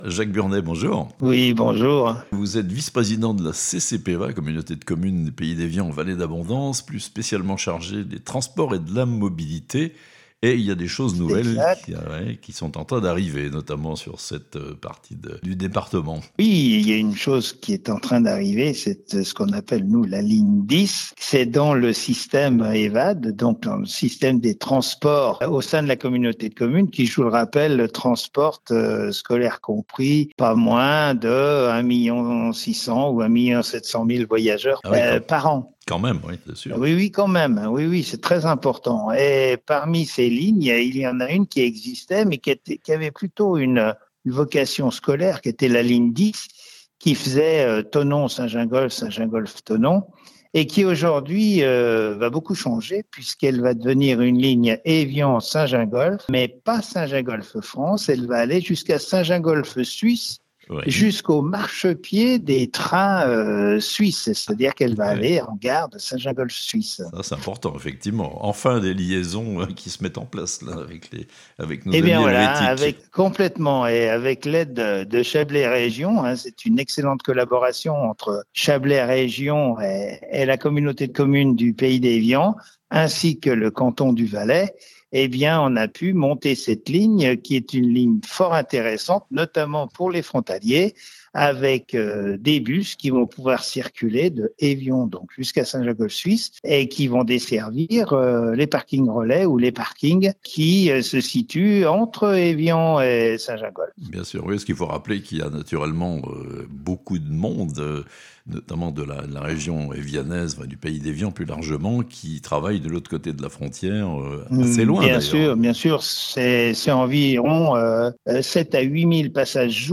La ligne 10 des transports en commun ÉVA’D est prolongée jusqu’à la gare de Saint-Gingolph Suisse (interview)